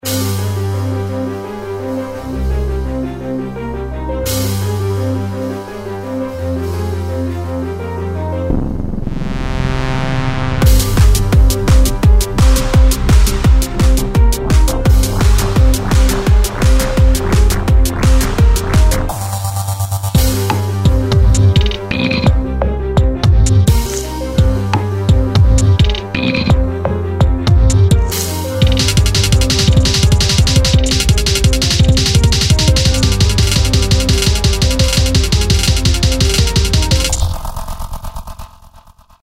音の連続が息をつく間もなく襲ってくるような感覚。
• テンポは高速（約150〜160BPM）。まさに「走っている」テンポ感。
• 音の密度が高めでスネアや効果音的なSEがテンポよく詰め込まれている。
• ストリングスや電子音、金属的な音が混在し混乱感を視覚的に表現
• 短い反復フレーズとアクセントの強い打楽器で、緊張の「圧」を演出。
フリーBGM ゲームBGM パニック 緊張感 逃走 アクション